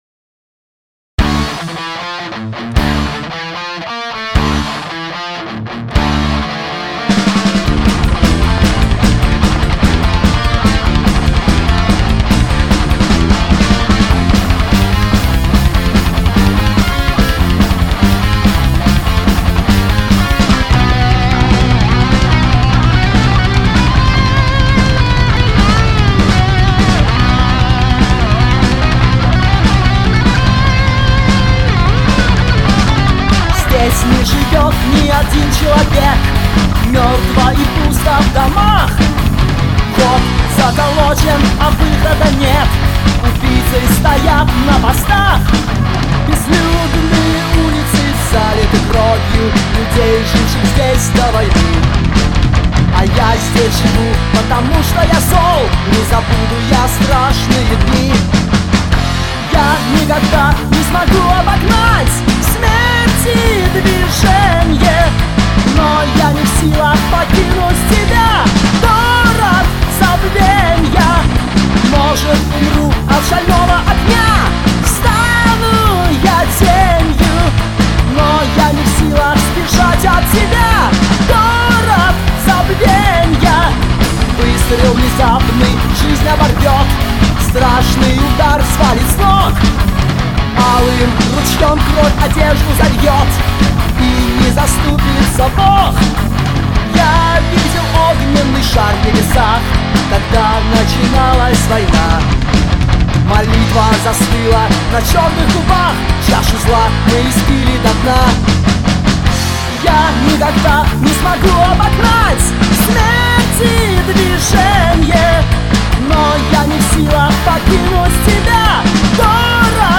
вокал, бас-гитара
гитара
барабаны
который стал бы работать в стиле heavy-metal.
первую студийную запись